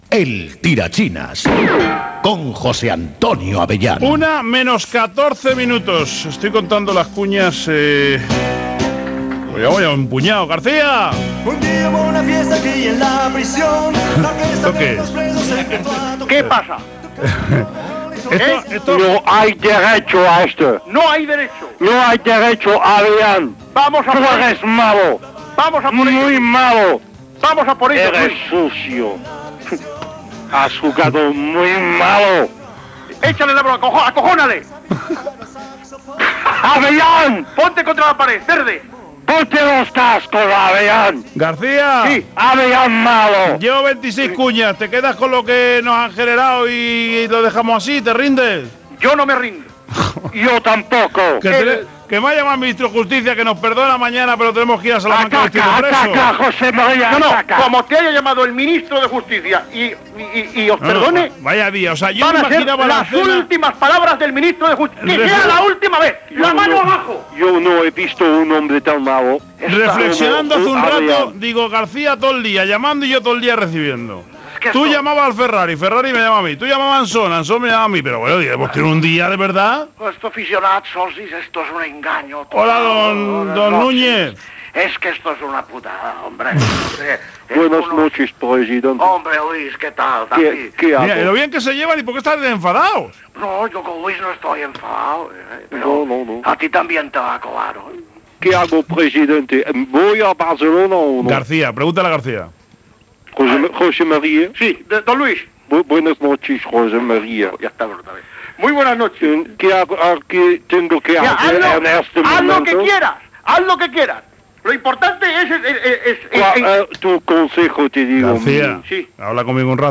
Repercussió de la trucada imitant a José María García a Josep Lluís Núñez i Louis van Gaal del dia anterior. Gènere radiofònic Esportiu Presentador/a Abellán, José Antonio